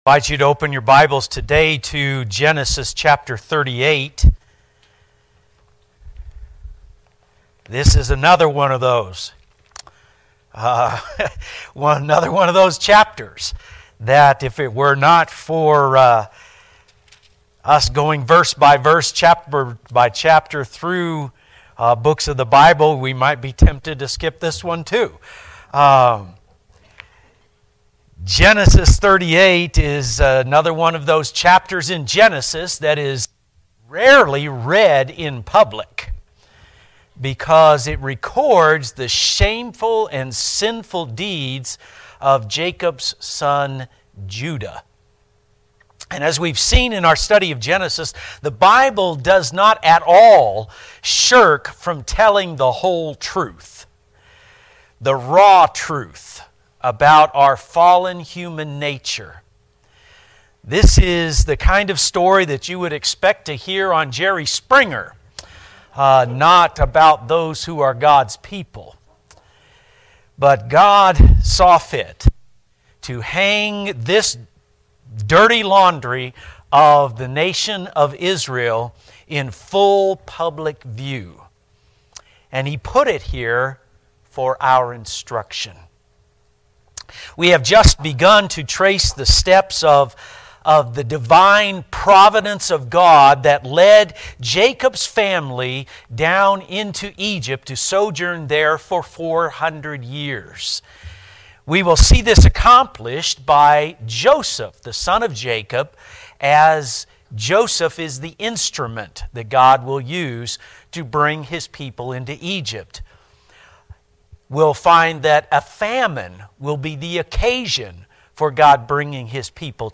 Sermons Archives - Page 14 of 41 - New Covenant Baptist Church